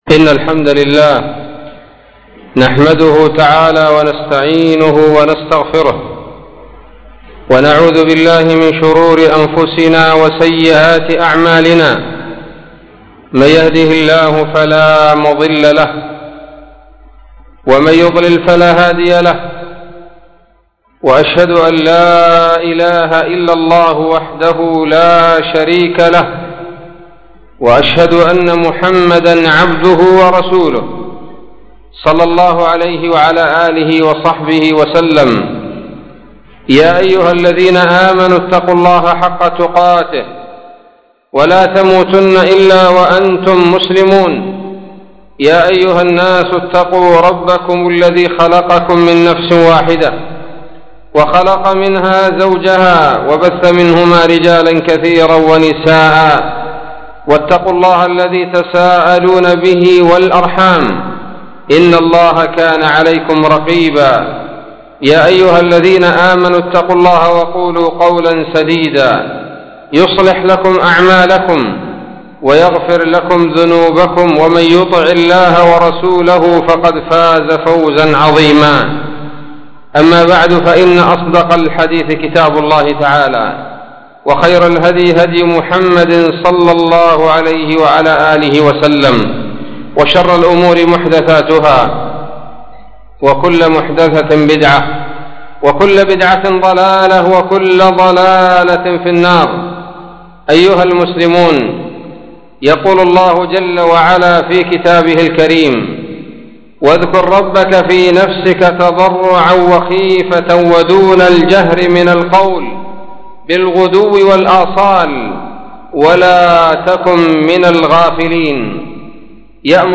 خطبة بعنوان : ((الخوف من الله))